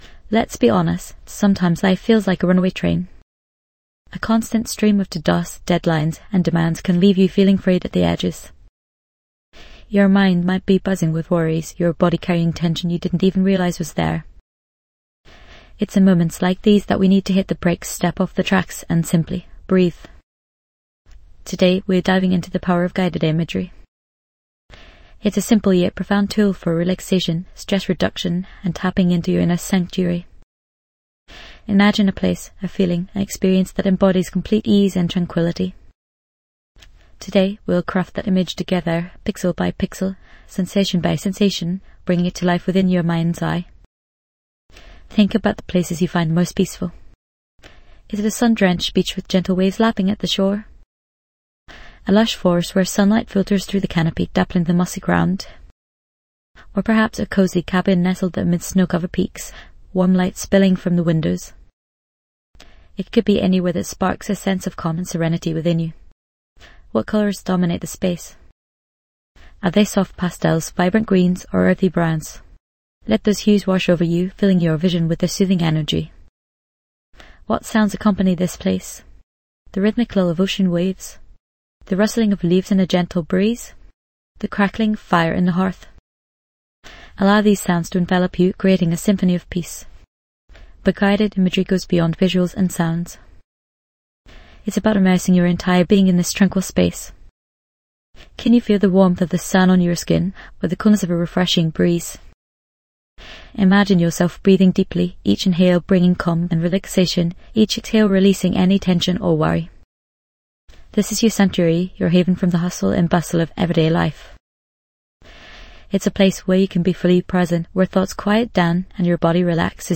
Journey towards deep relaxation with our soothing guided imagery meditation. This immersive experience invites you to visualize a serene beach at sunset, allowing stress and tension to melt away.
This podcast is created with the help of advanced AI to deliver thoughtful affirmations and positive messages just for you.